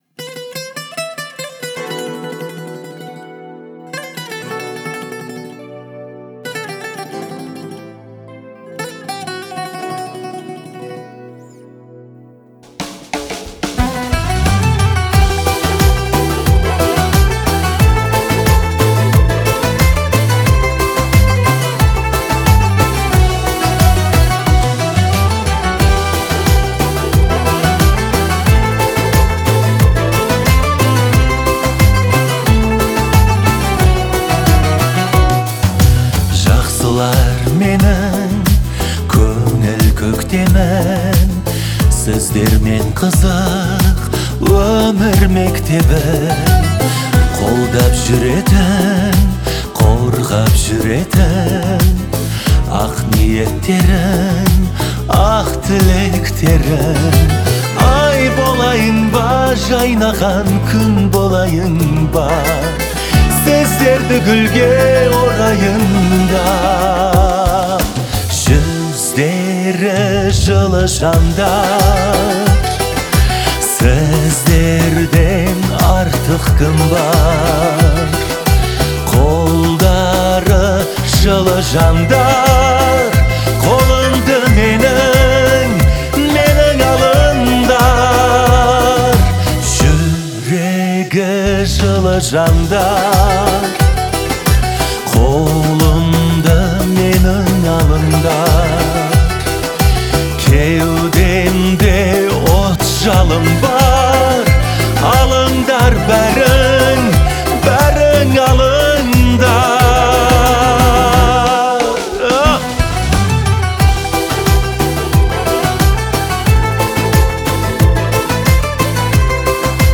сочетая нежный голос и выразительные интонации.